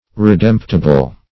redemptible - definition of redemptible - synonyms, pronunciation, spelling from Free Dictionary
Redemptible \Re*demp"ti*ble\ (r[-e]*d[e^]mp"t[i^]*b'l)